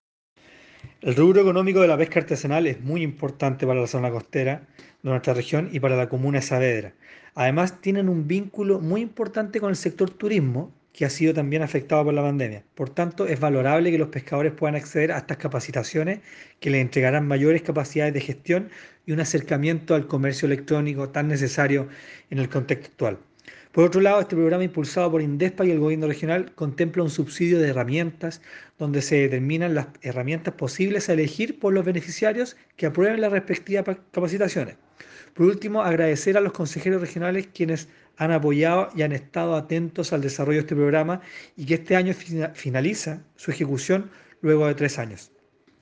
cuña-seremi-economia-francisco-lopez.mp3